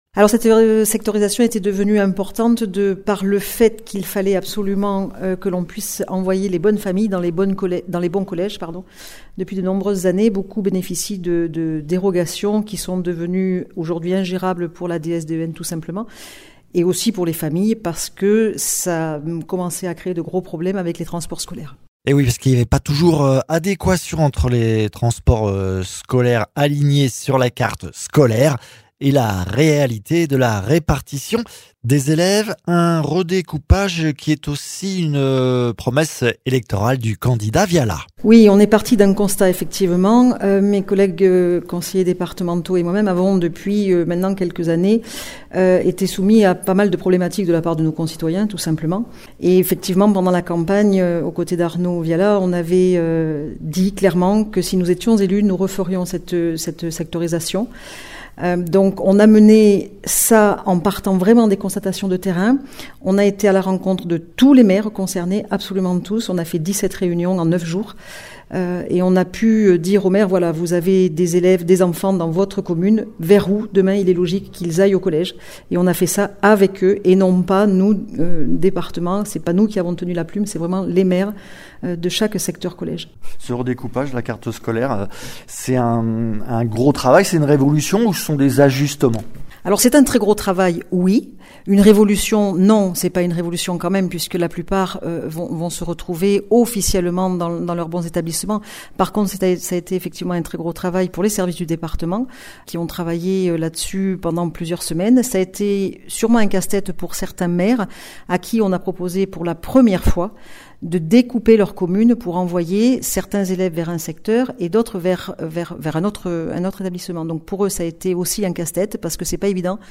Interviews
Invité(s) : Magali Bessaou, Vice Présidente du conseil départemental de l’Aveyron en charge de la jeunesse, des collèges, de l’enseignement supérieur et de l’immobilier départemental